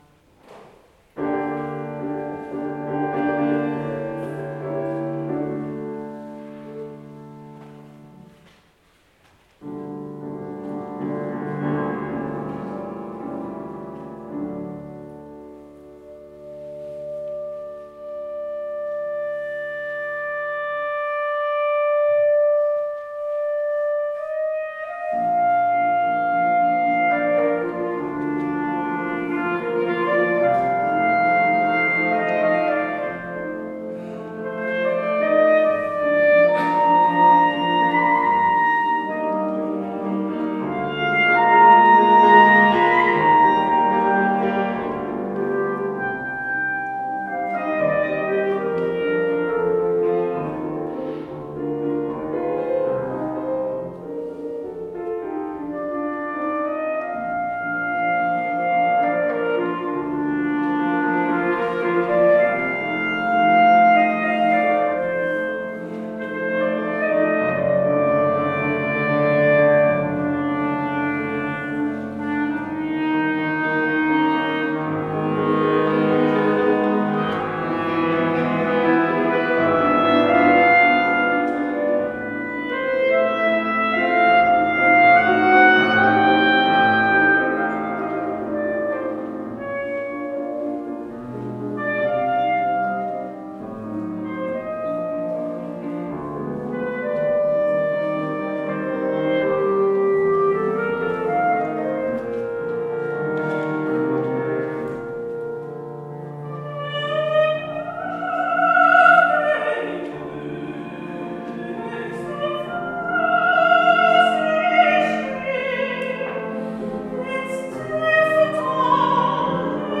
Abendmusik in der Magdalenabergkirche